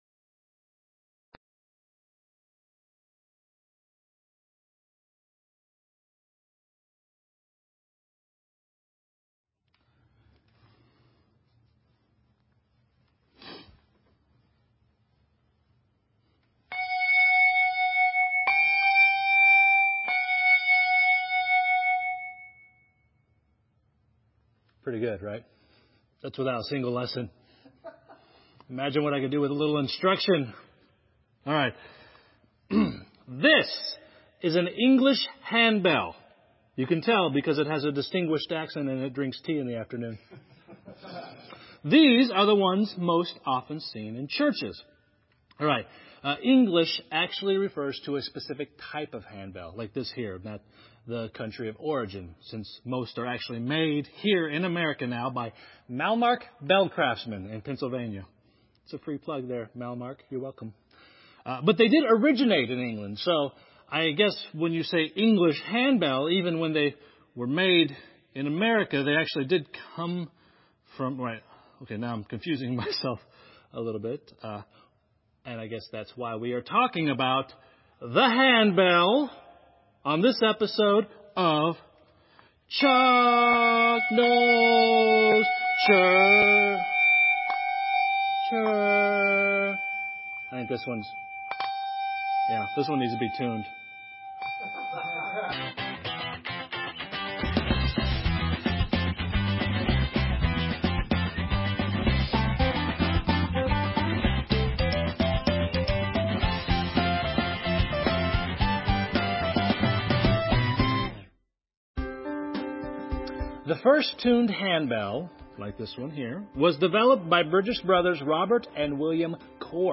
Sermon:WWJD?
Note: The prelude begins 15 minutes into the video but at the beginning of the audio file
Worship Service